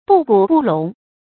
不瞽不聾 注音： ㄅㄨˋ ㄍㄨˇ ㄅㄨˋ ㄌㄨㄙˊ 讀音讀法： 意思解釋： 意為不故作癡呆，不裝聾作啞，就不能當好阿公阿婆。